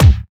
• Acoustic Kick Single Shot C# Key 352.wav
Royality free steel kick drum sound tuned to the C# note. Loudest frequency: 532Hz
acoustic-kick-single-shot-c-sharp-key-352-KsA.wav